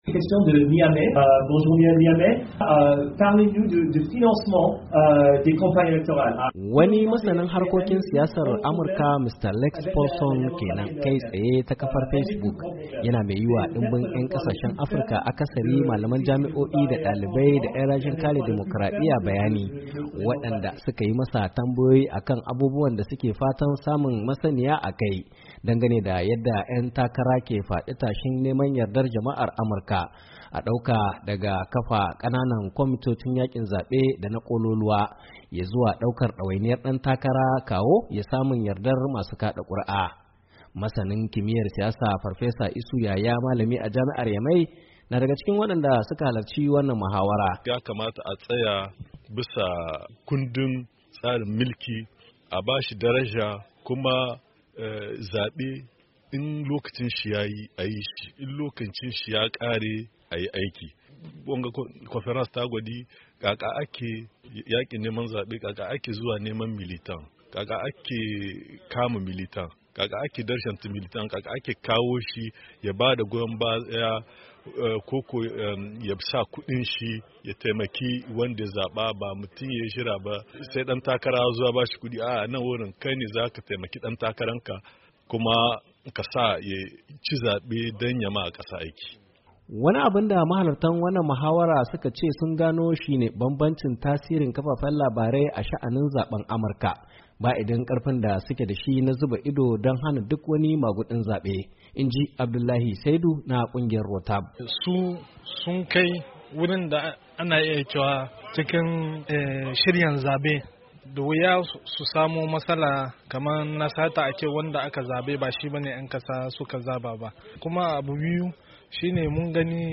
WASHINGTON DC —